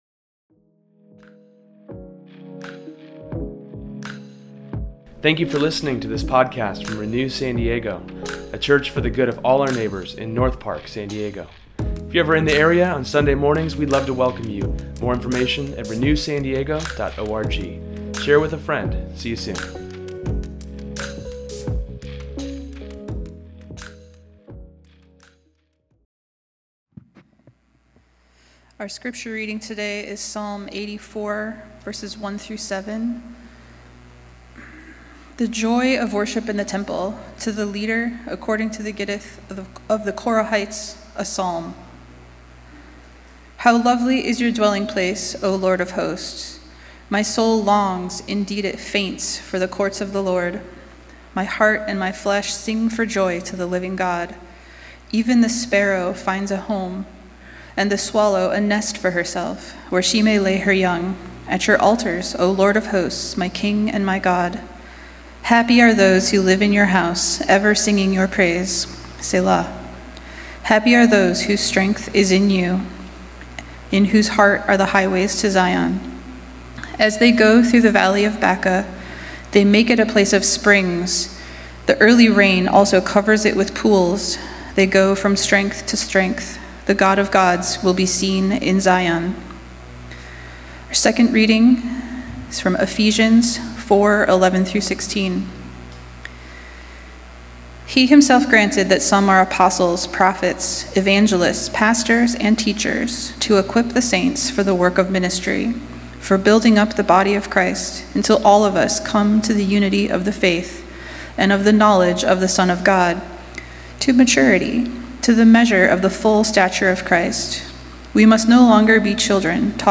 Today’s sermon explores the meaning behind being a member of the church, and the importance and meaning it can bring to someone’s life.